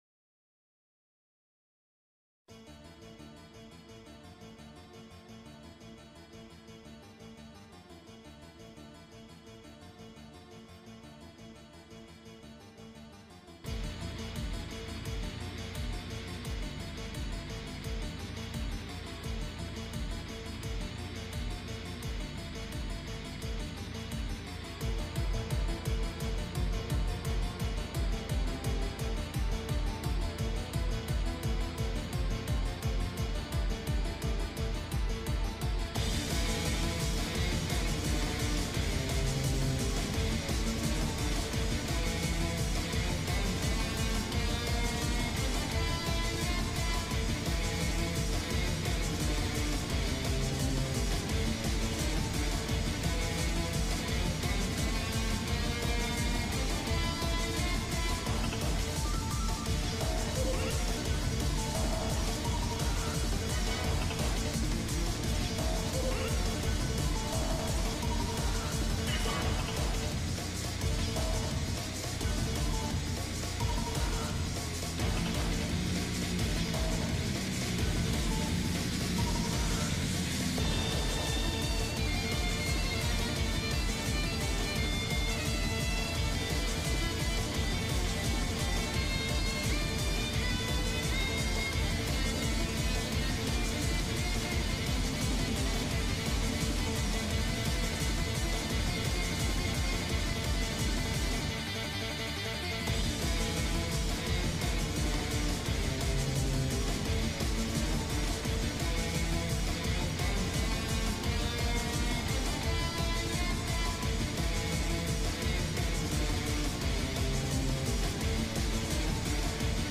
chase theme* (Slowed + Reverb)